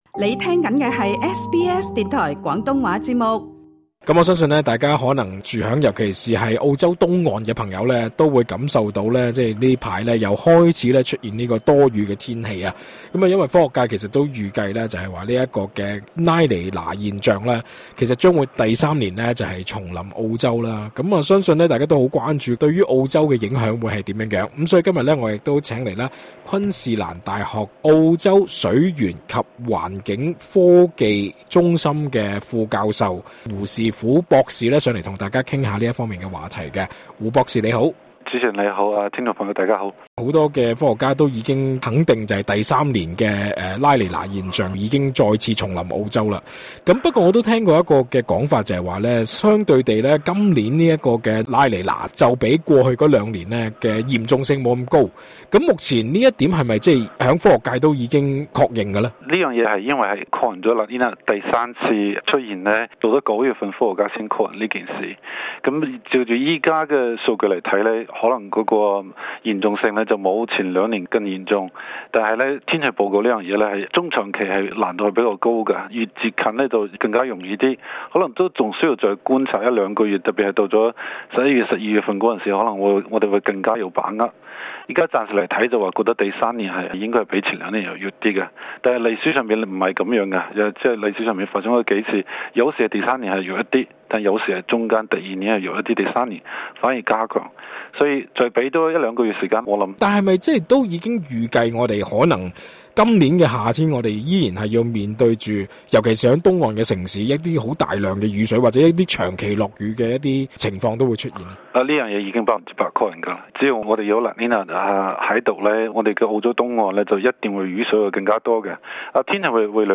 隨著科學界證實澳洲無可避免連續第三年受「拉尼娜現象」影響，雖然有消息指，按目前數據顯示今年的拉尼娜現象或會減弱，但環境專家在接受本台訪問時指，情況仍未容樂觀，而政府不能再漠視三年多前專家提出的氣候建議。